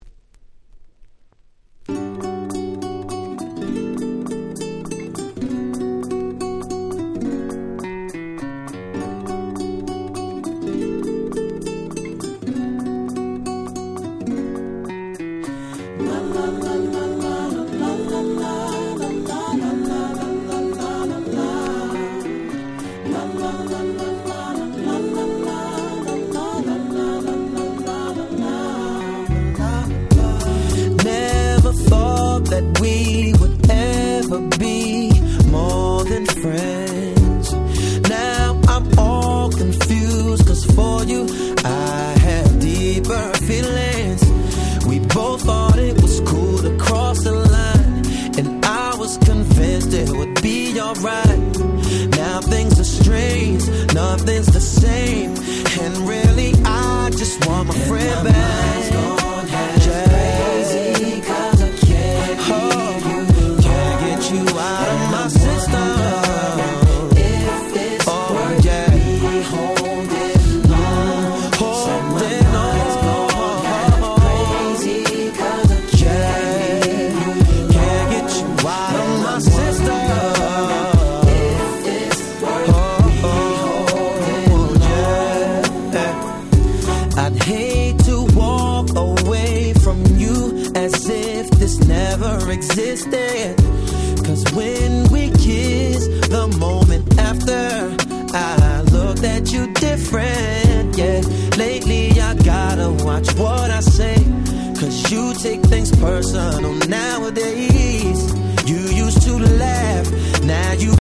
02' Big Hit R&B / Neo Soul !!
まったりとした最高のバラード。